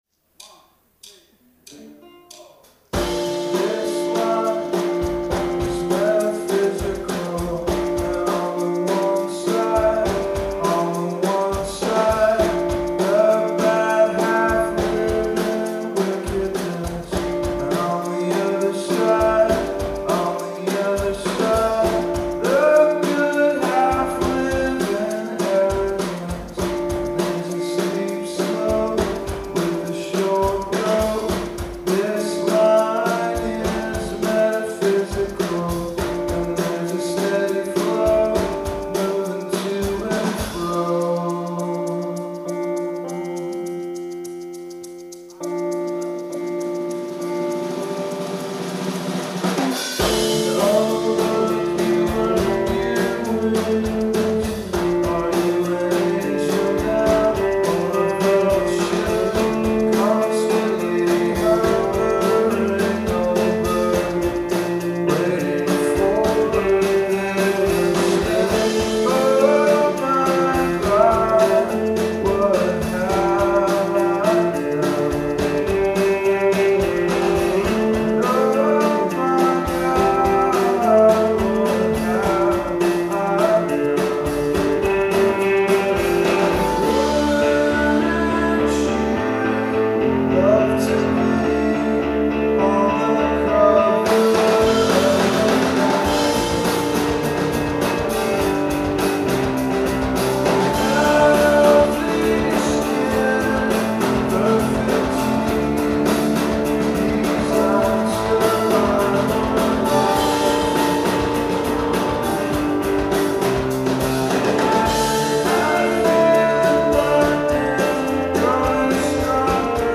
Live at the Somerville Theater, Somerville, MA